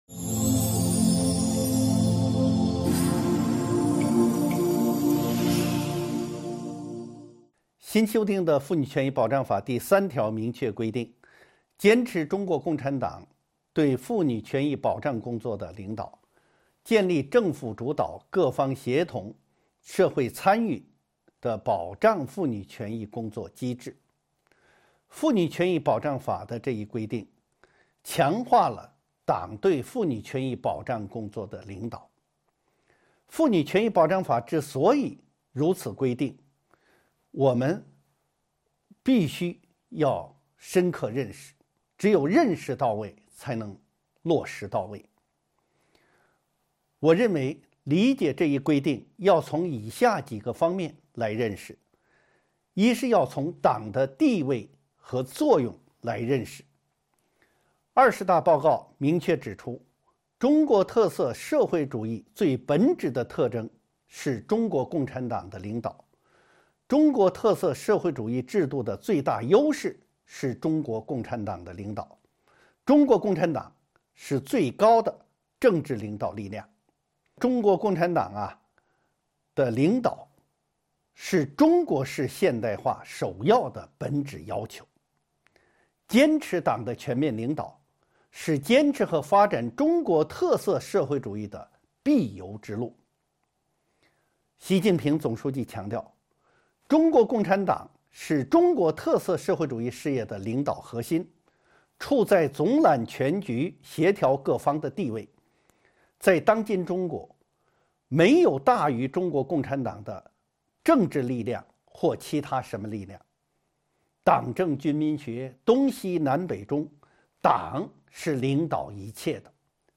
音频微课:《中华人民共和国妇女权益保障法》8.党的领导与国家责任